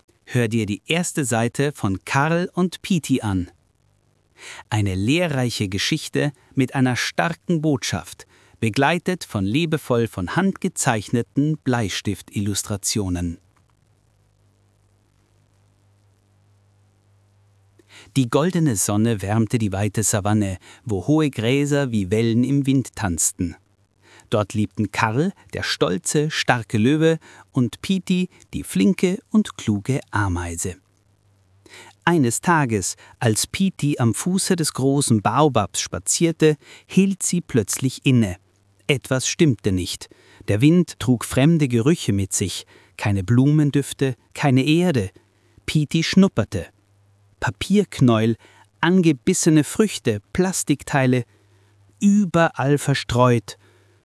Lesungen (Audio)